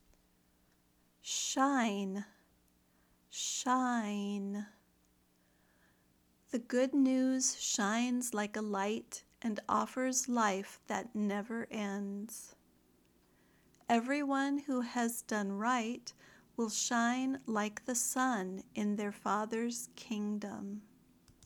/ʃaɪn/ (verb)